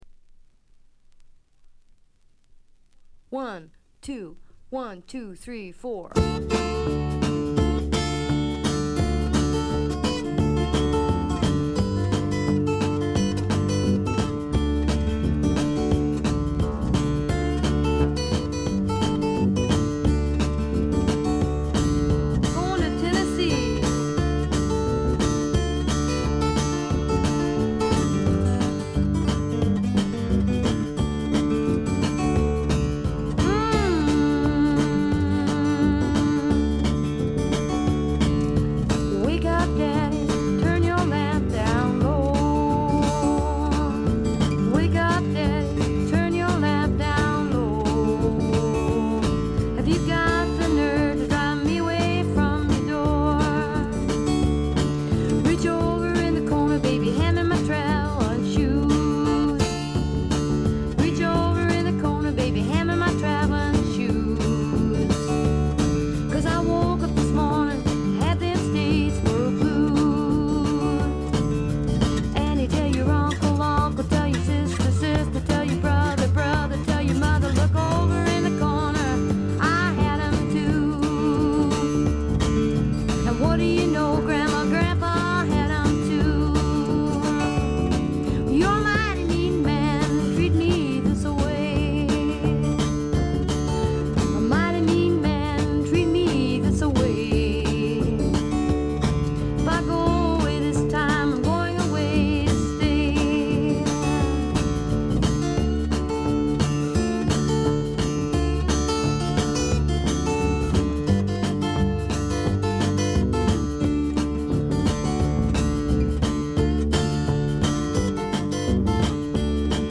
ごくわずかなノイズ感のみ。
トリオ編成にゲストが加わる編成ですがブルースを基調にアーシーなサウンドを聴かせてくれます。
試聴曲は現品からの取り込み音源です。